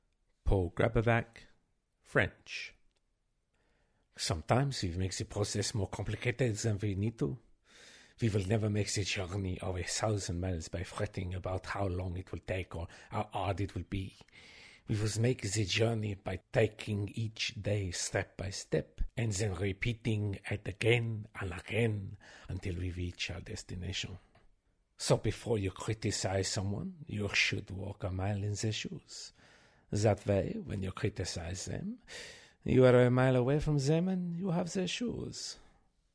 French Accents
Male, 40s